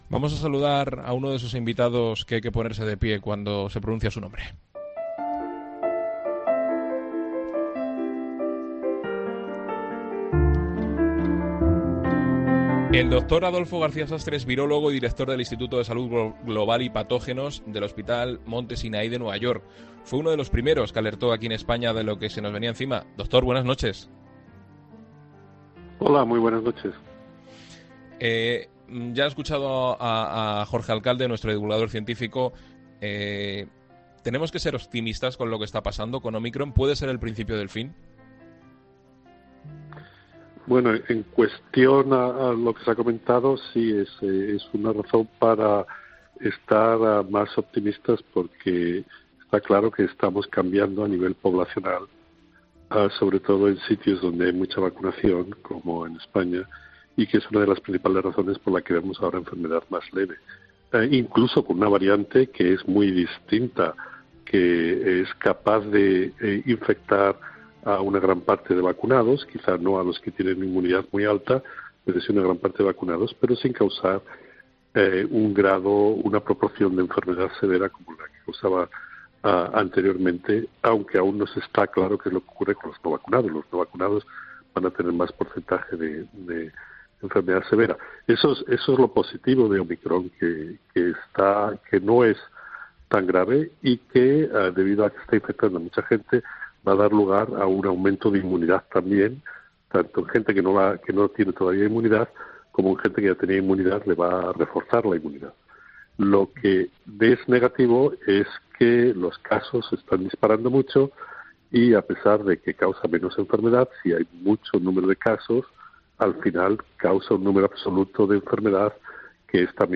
El experto ha pasado por los micrófonos de 'La Linterna' para analizar las últimas noticias que protagoniza el virus